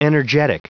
Prononciation du mot energetic en anglais (fichier audio)
Prononciation du mot : energetic